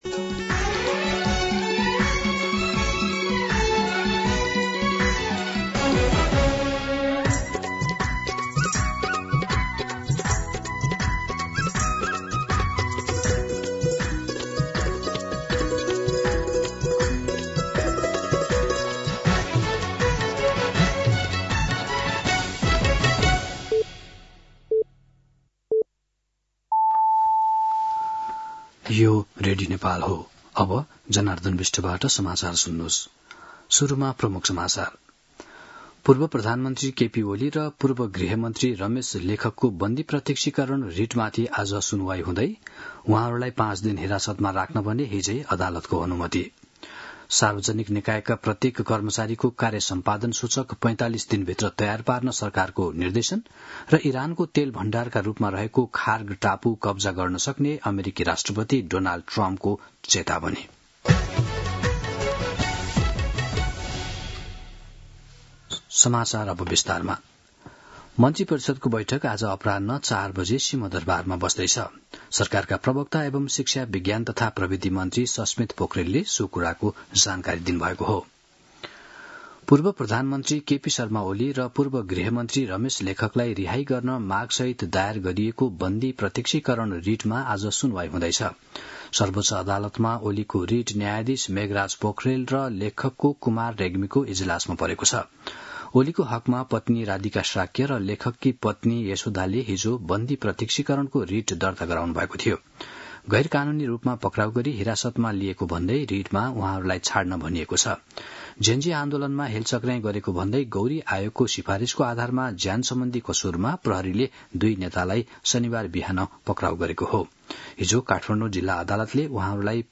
An online outlet of Nepal's national radio broadcaster
दिउँसो ३ बजेको नेपाली समाचार : १६ चैत , २०८२
3pm-Day-News-12-16.mp3